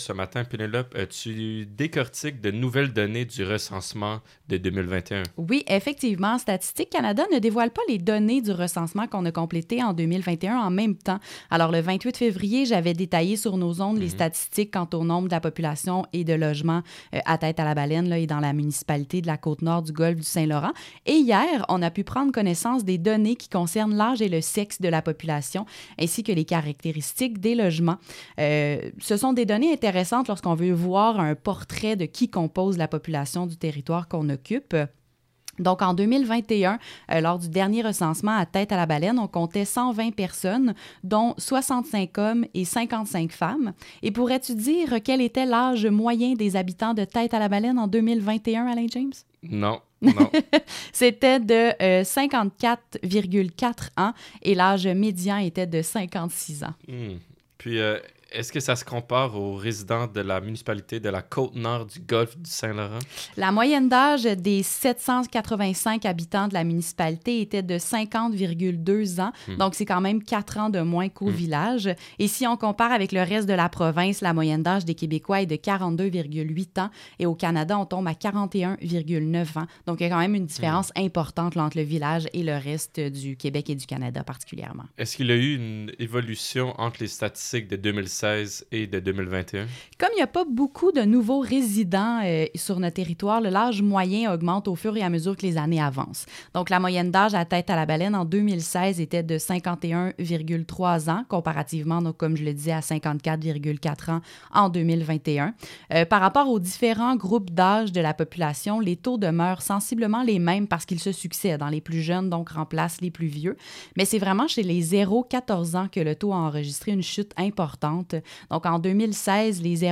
Recensement-segment-radio.mp3